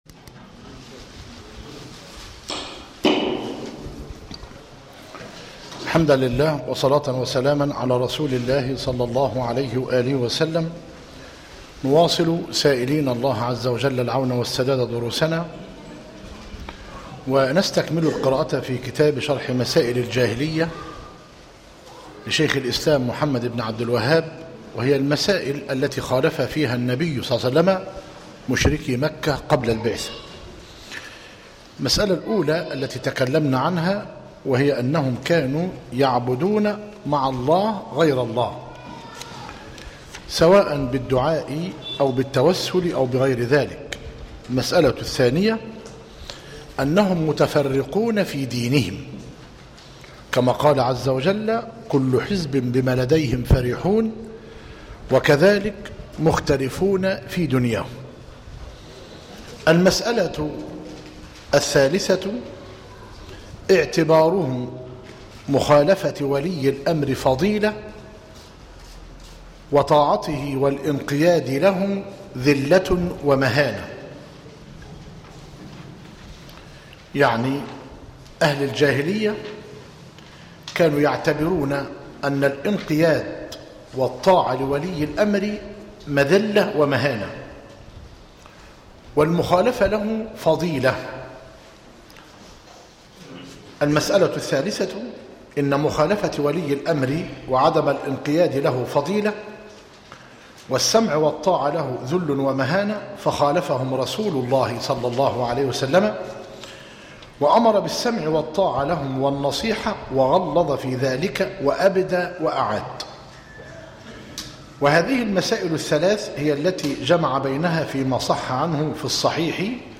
شرح مسائل الجاهلية للعلامة الفوزان - مسجد عباد الرحمن - المنايل - كفر حمزة - قليوبية - المحاضرة الثالثة - بتاريخ 9- جماد آخر- 1436هـ الموافق 29- مارس - 2015 م